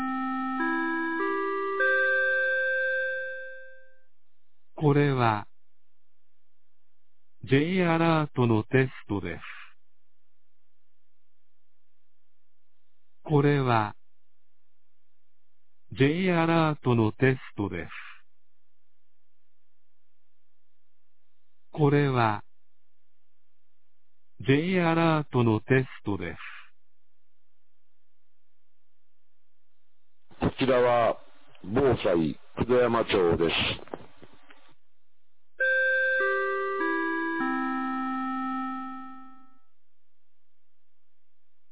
2025年05月28日 11時01分に、九度山町より全地区へ放送がありました。